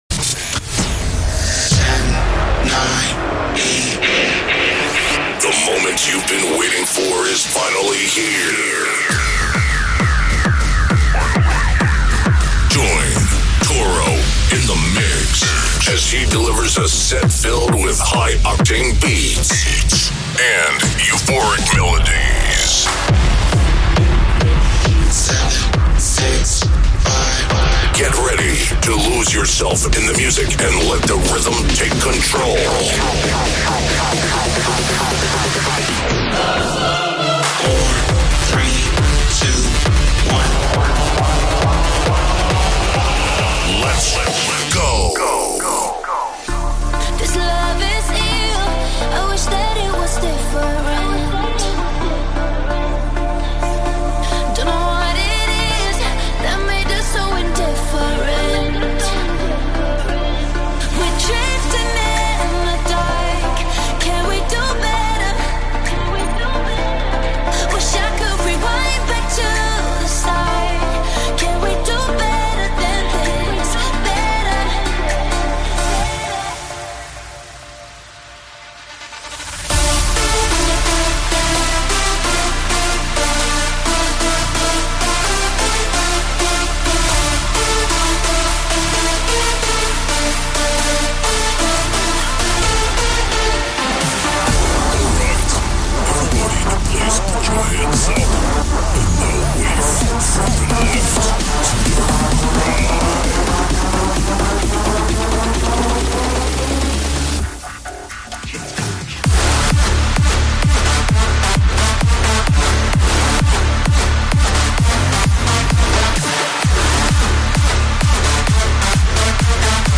" the ultimate dance and trance music podcast.